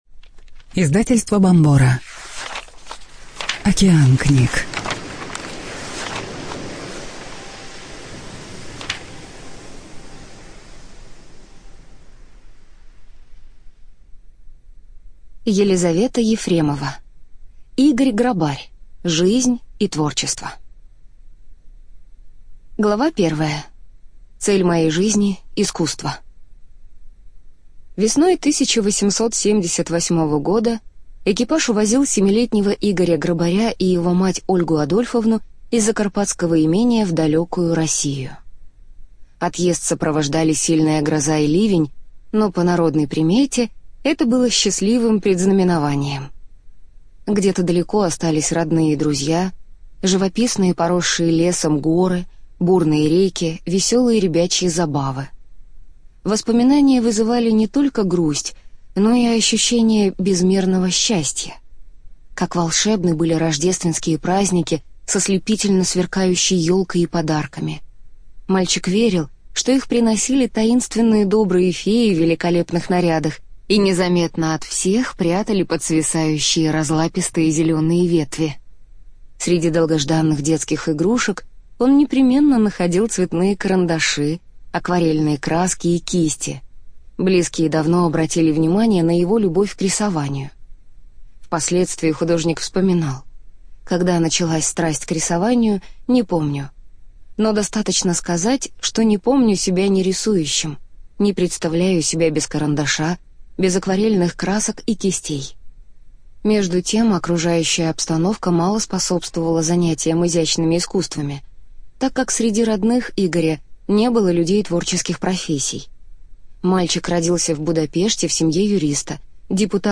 ЖанрБиографии и мемуары
Студия звукозаписиБомбора